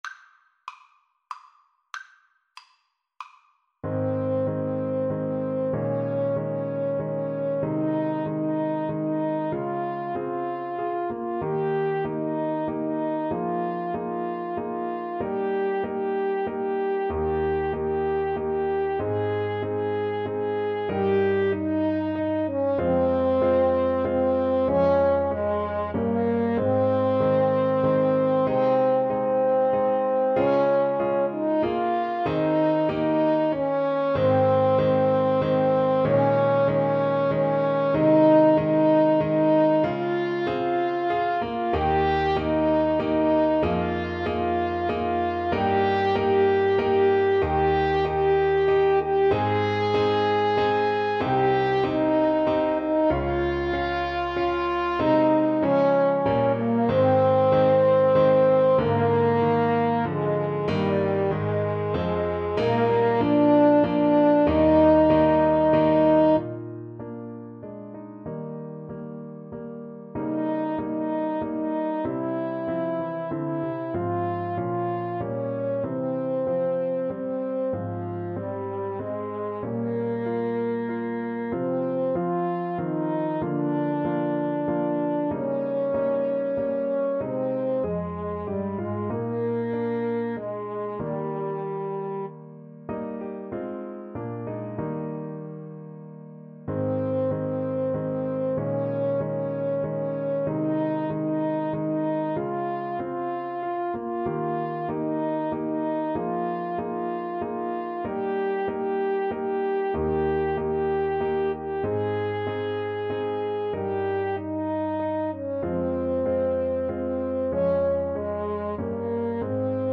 =95 Andante